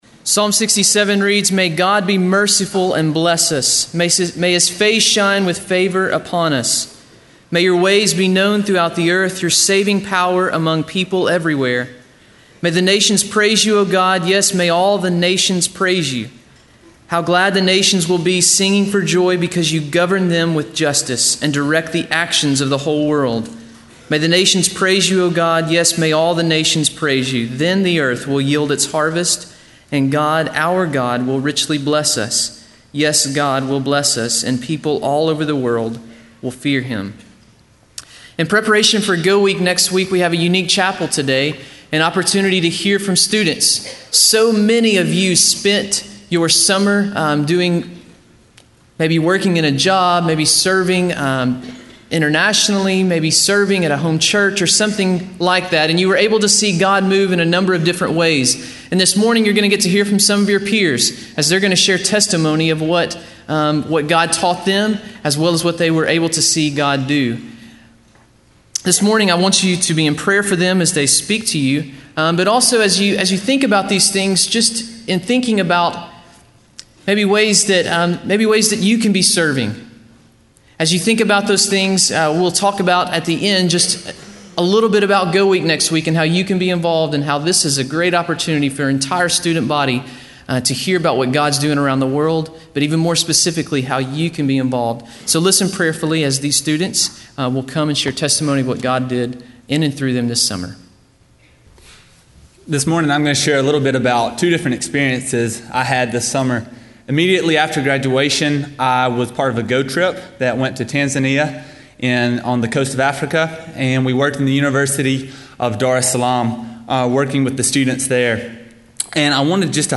Chapel: Student Summer Ministry Experiences